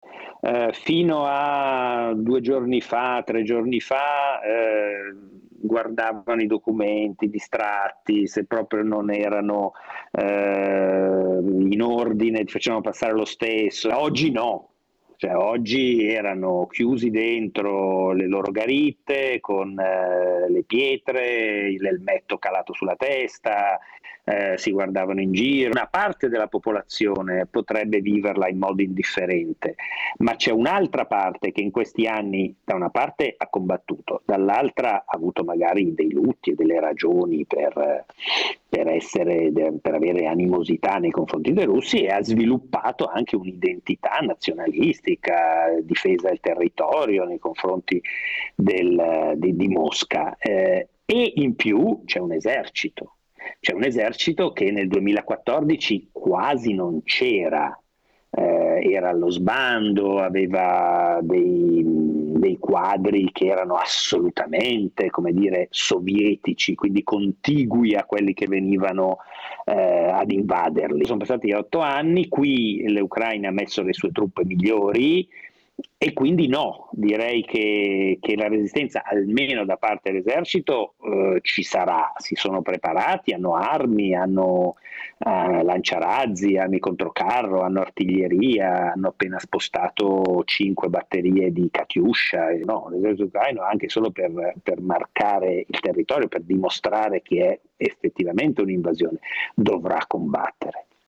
a Mariupol una cittadina portuale ucraina che si trova in una posizione nevralgica ed è praticamente sotto assedio.